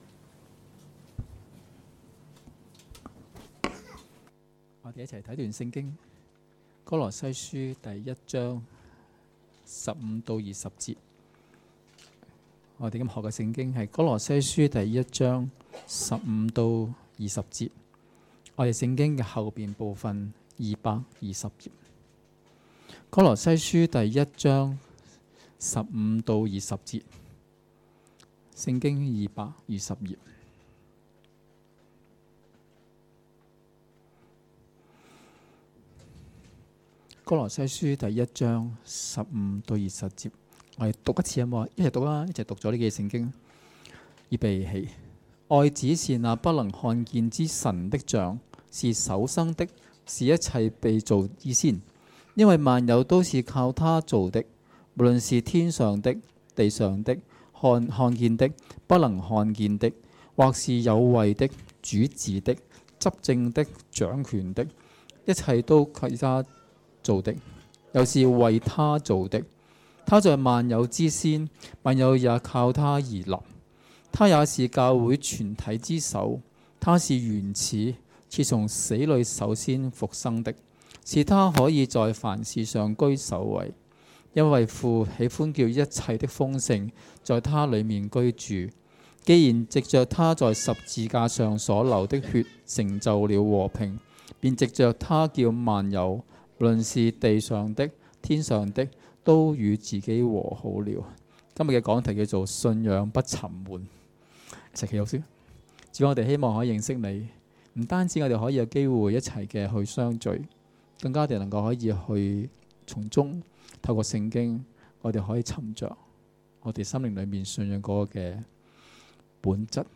2016年8月6日及7日崇拜
2016年8月6日及7日崇拜講道